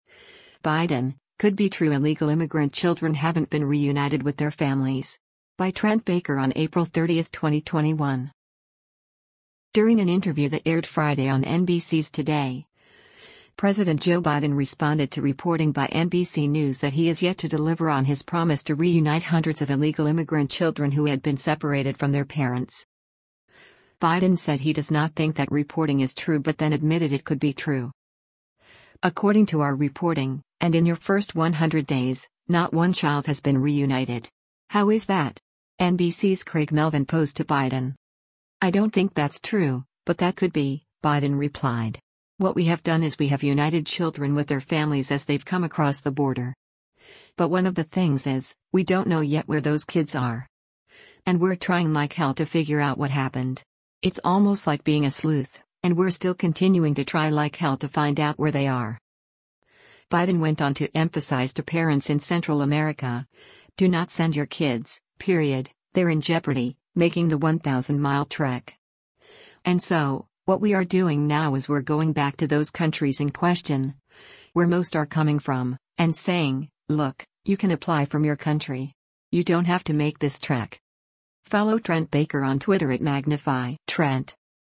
During an interview that aired Friday on NBC’s “Today,” President Joe Biden responded to reporting by NBC News that he is yet to deliver on his promise to reunite hundreds of illegal immigrant children who had been separated from their parents.